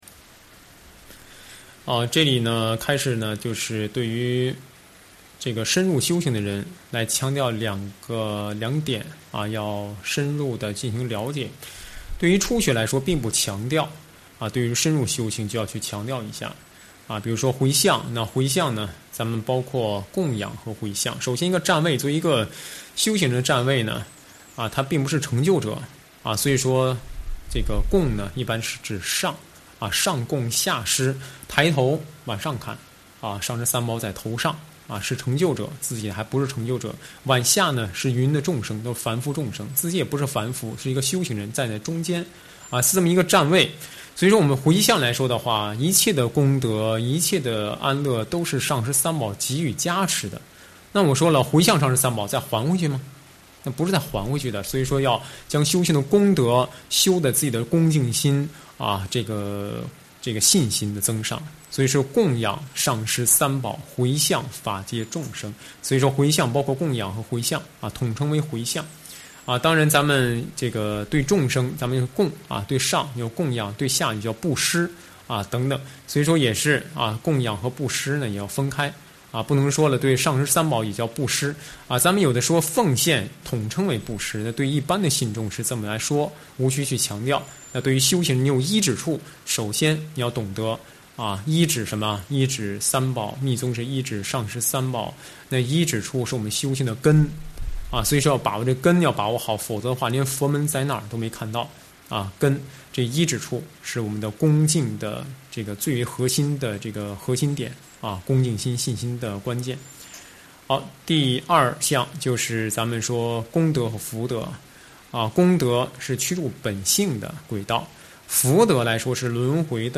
上师语音开示